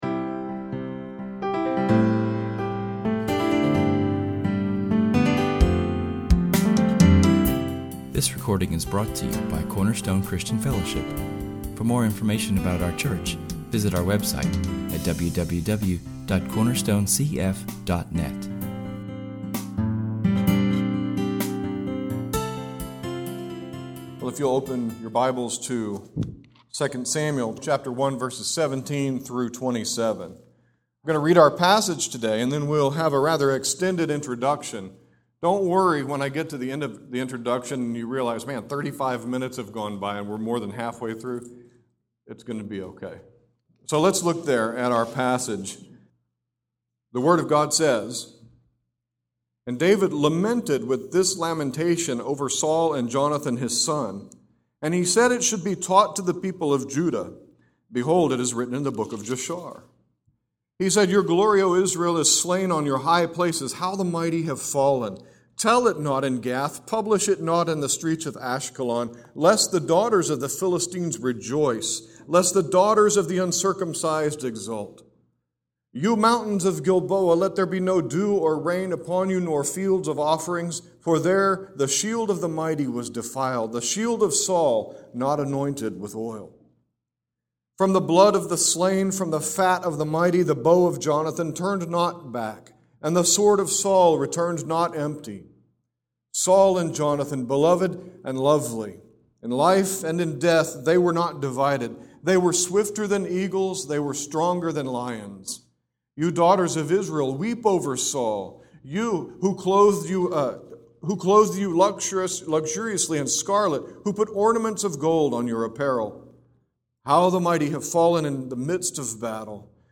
2nd Samuel – The Death Sermon